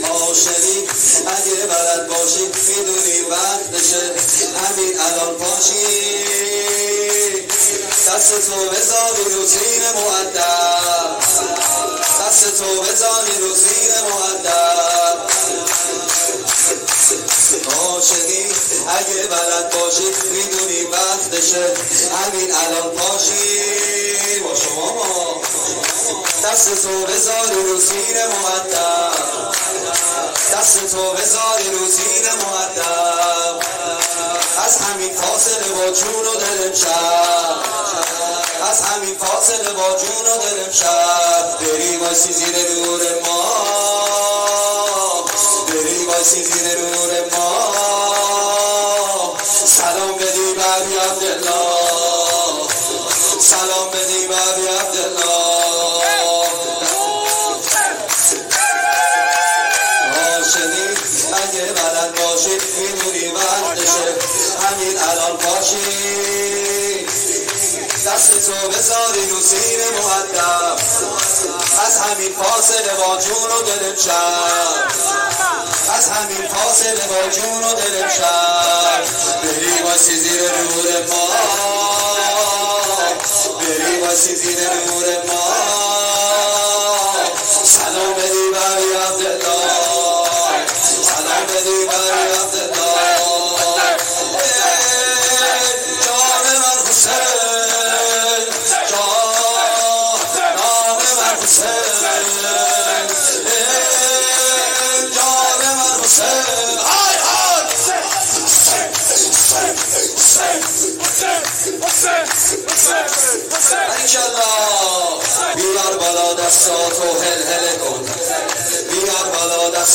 شور میلاد امام زمان